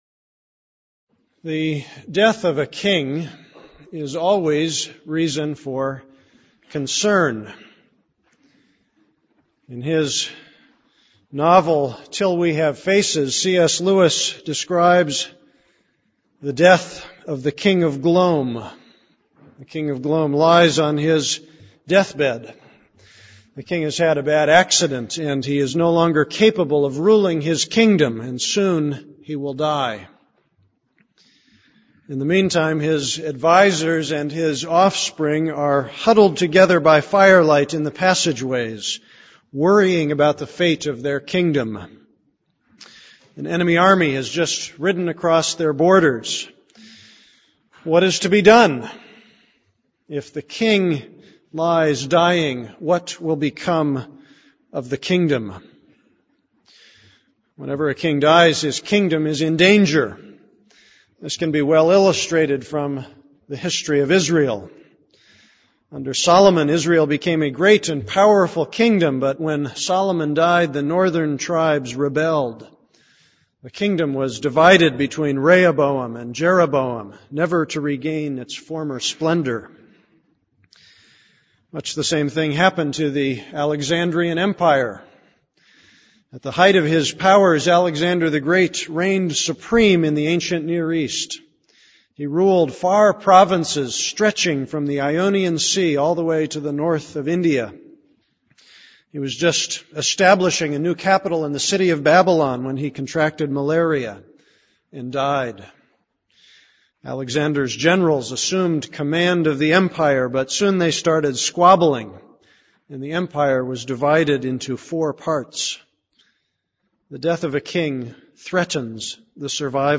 This is a sermon on Jeremiah 33:17-26.